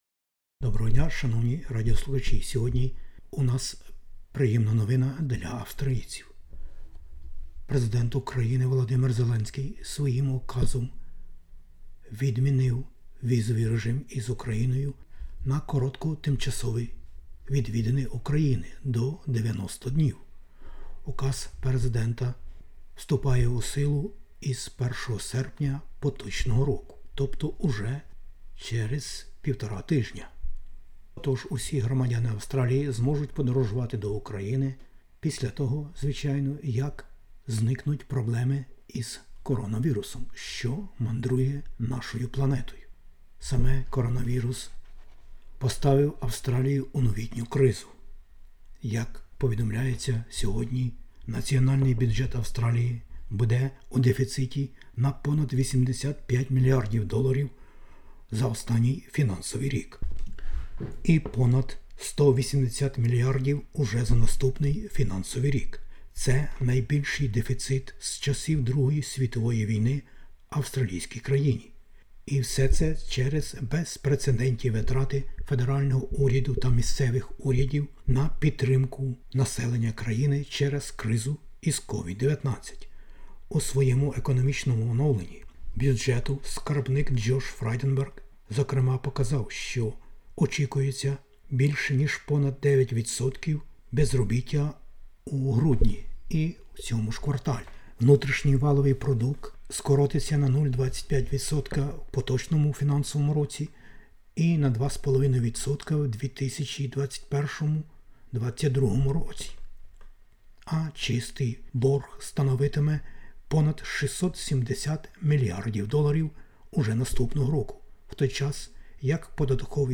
SBS НОВИНИ УКРАЇНСЬКОЮ